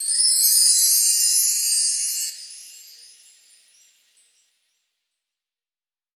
Dilla Chime 01.wav